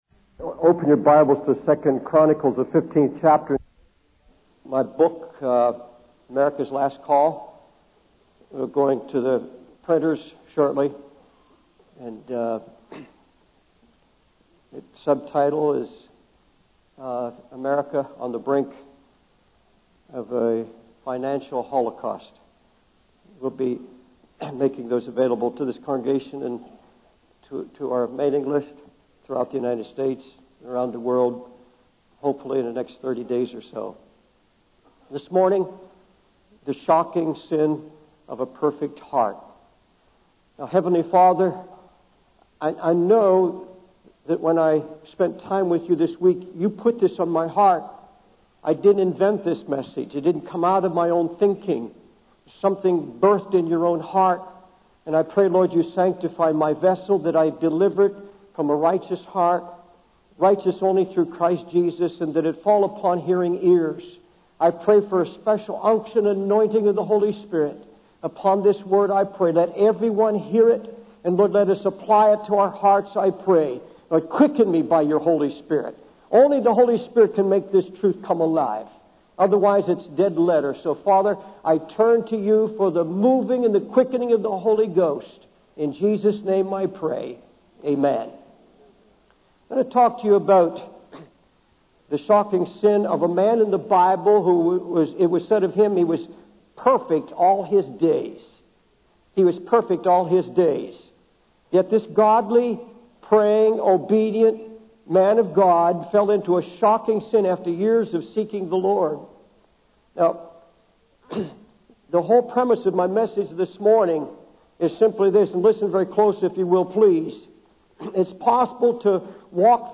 In this sermon, the preacher discusses the sin of King Asa, who had a perfect heart but made a grave mistake by relying on his own plans instead of trusting in God.